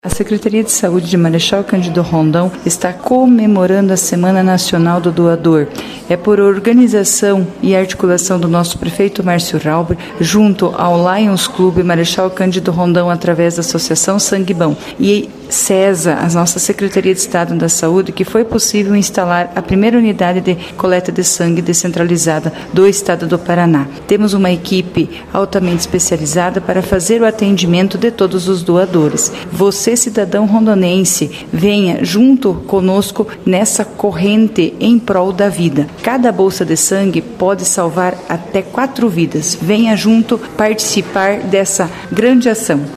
Nesta semana especial, os doadores têm sido recebidos com ações de reconhecimento, conforme destaca a secretária rondonense de saúde, Marciane Specht…